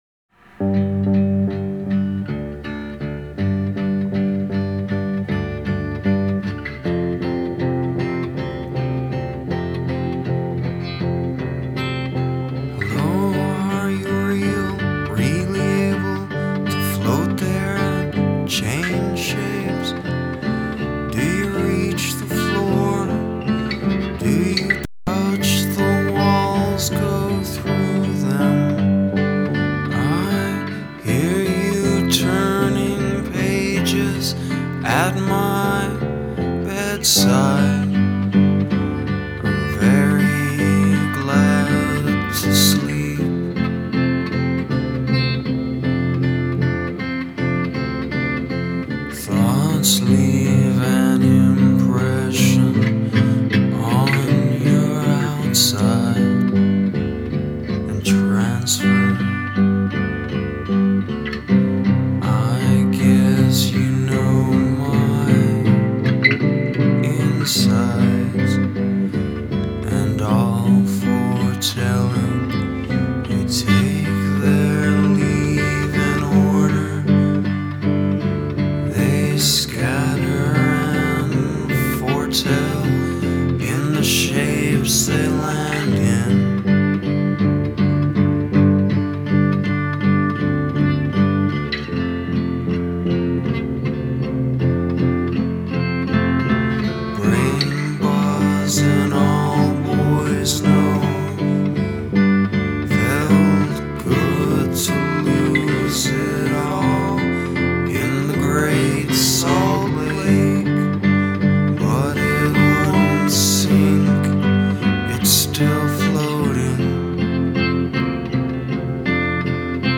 guitar part